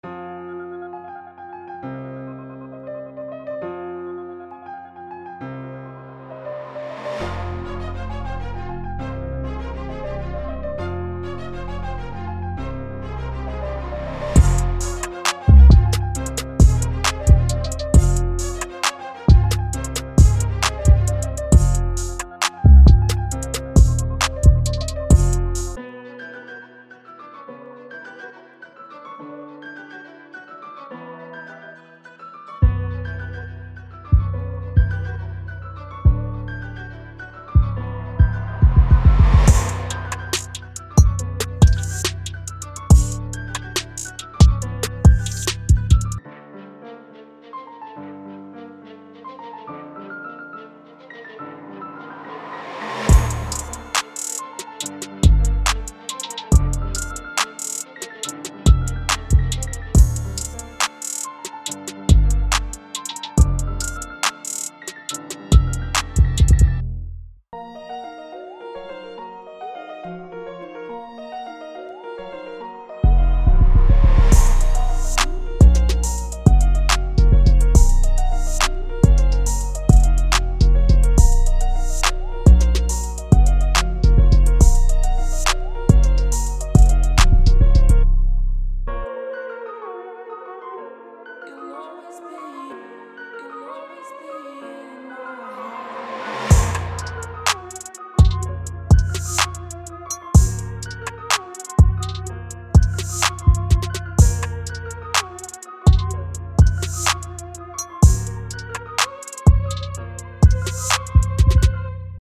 音色库将为您带来轻松愉悦、令人忍不住点头的氛围。